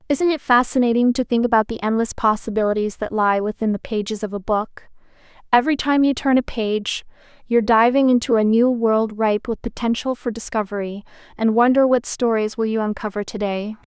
female_normal.wav